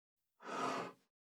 386,机の上をスライドさせる,スー,ツー,
効果音